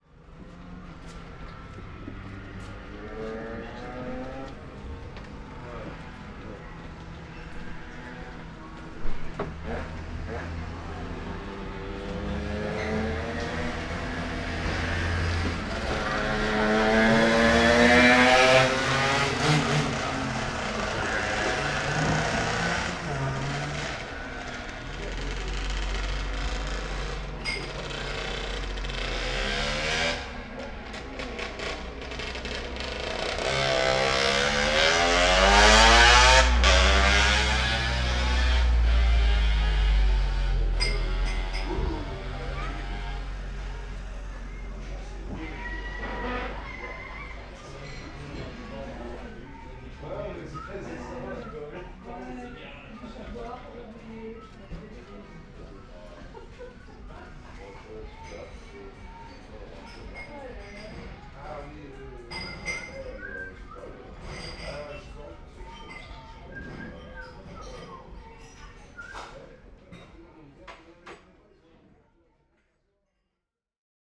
Motorbikes passing in front of the Quincy terrasse & at the end, the background music inside the bar.